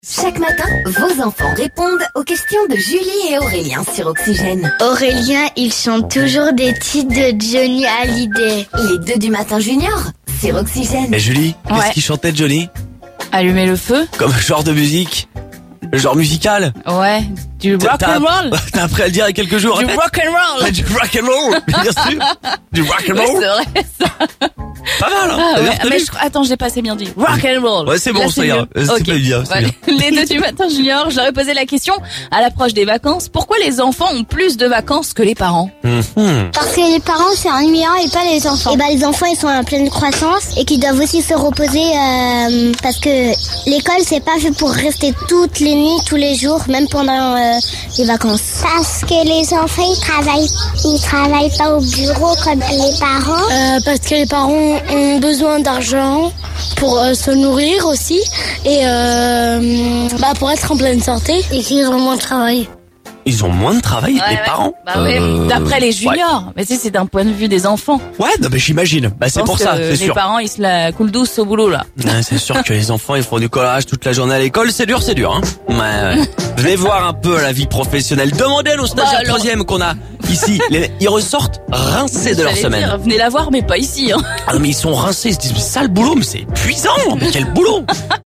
Ecoutons les enfants Seine-et-Marnais nous expliquer pourquoi les parents ont moins de vacances qu'eux...